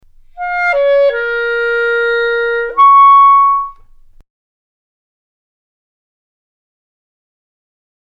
The production of a smoother legato in the first movement of the F Minor Sonata , Op. 120 No. 1 by Johannes Brahms is facilitated with the alternate E-flat diagramed in Example #4. Contrary finger motion is completely avoided, as is the slide that would be required with the use of the standard E-flat. 7